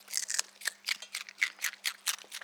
TerraZoo_Mammal_RingTailedLemur_EatingCelery_KMR81i.wav